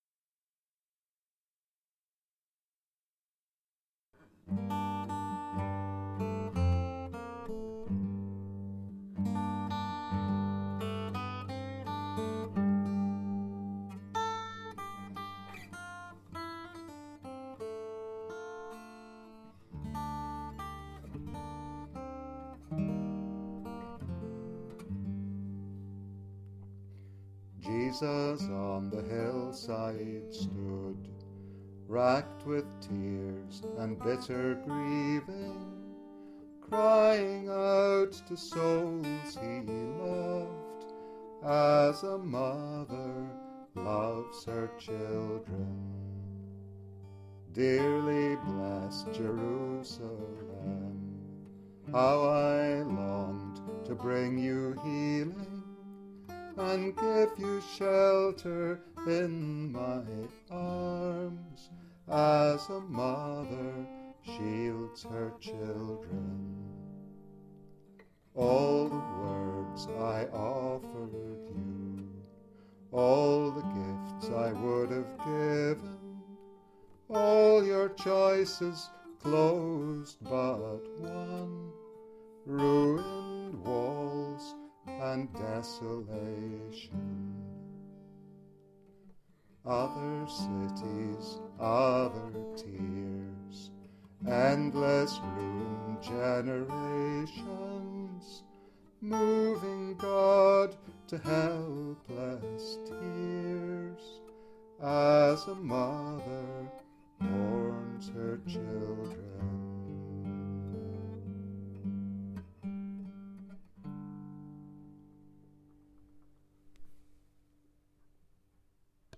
During holy week, Jesus mourns for the doomed city. Set to one of the most perfect Scots traditional tunes, skippin' barfit thro the heather.